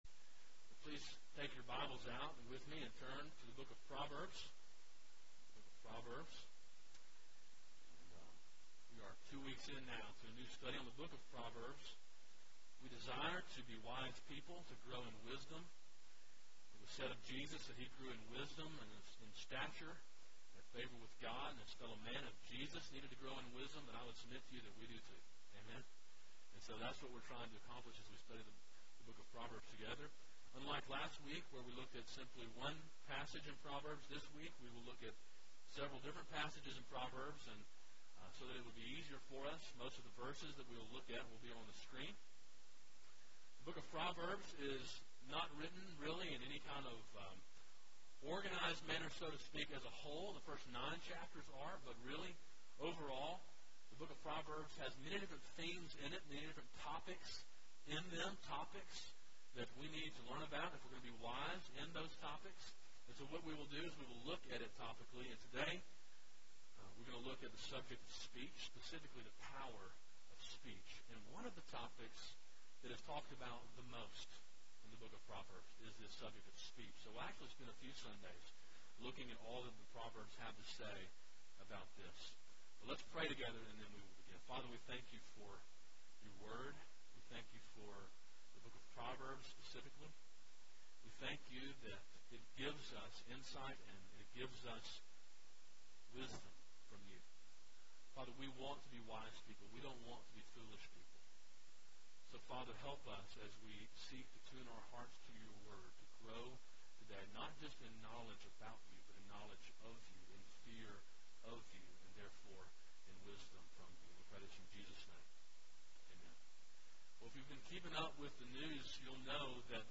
A sermon in a series on the book of Proverbs.
july-1-2012-morning-sermon.mp3